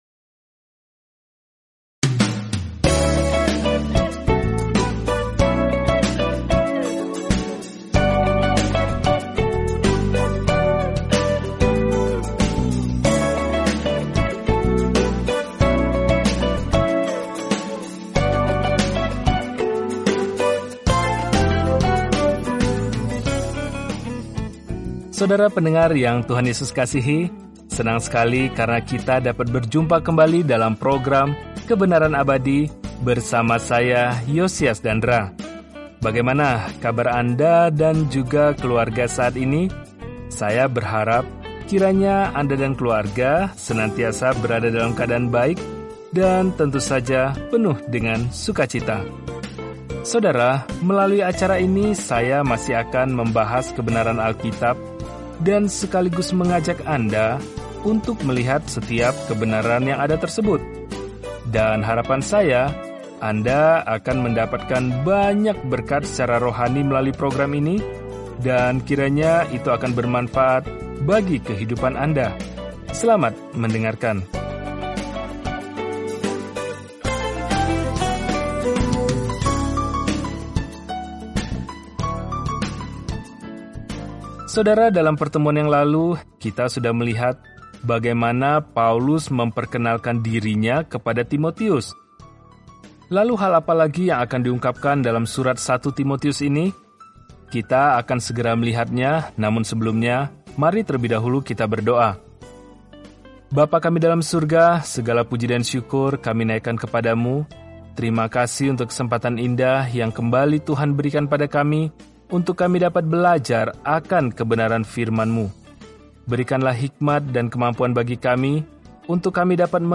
Firman Tuhan, Alkitab 1 Timotius 1:3-7 Hari 2 Mulai Rencana ini Hari 4 Tentang Rencana ini Surat pertama kepada Timotius memberikan indikasi praktis bahwa seseorang telah diubah oleh Injil – tanda-tanda kesalehan yang sejati. Telusuri 1 Timotius setiap hari sambil mendengarkan pelajaran audio dan membaca ayat-ayat tertentu dari firman Tuhan.